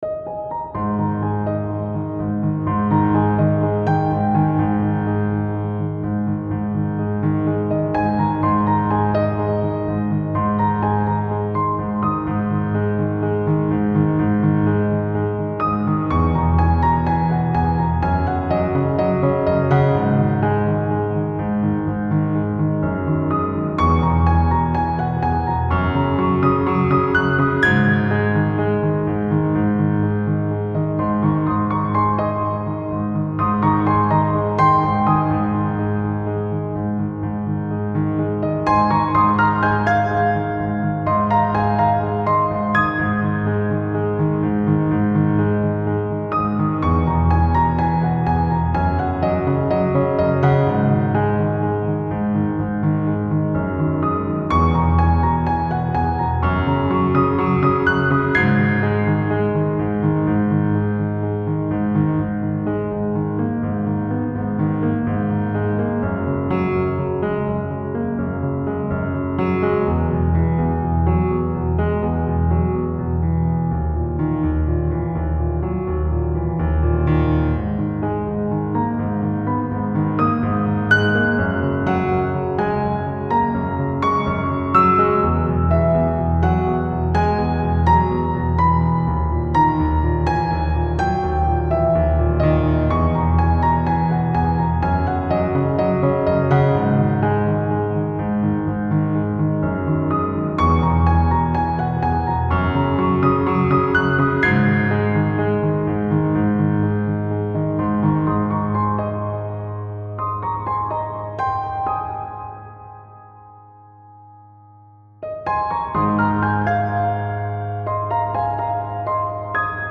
Style Style Soundtrack
Mood Mood Calming, Relaxed, Uplifting
Featured Featured Piano
BPM BPM 125